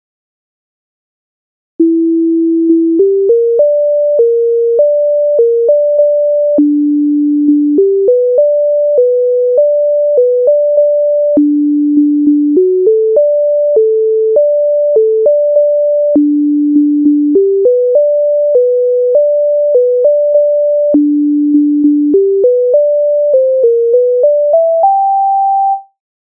MIDI файл завантажено в тональності G-dur